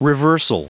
Prononciation du mot reversal en anglais (fichier audio)
Prononciation du mot : reversal